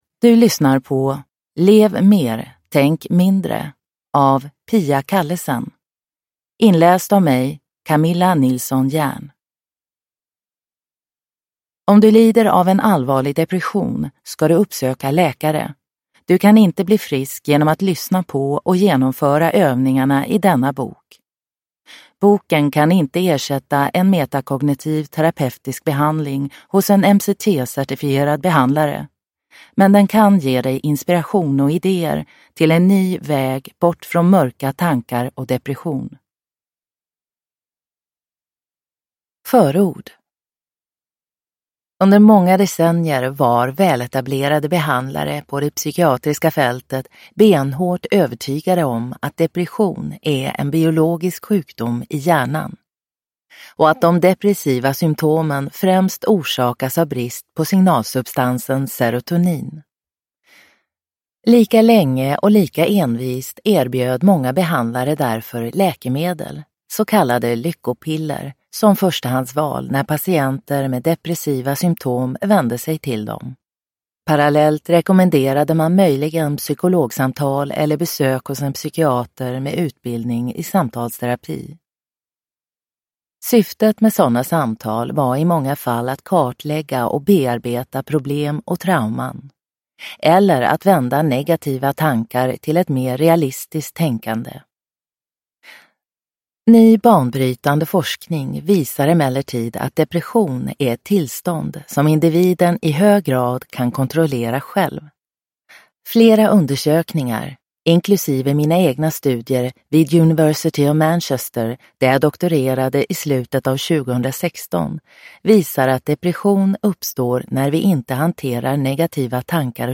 Lev mer, tänk mindre : bli fri från nedstämdhet och depression med metakognitiv terapi – Ljudbok – Laddas ner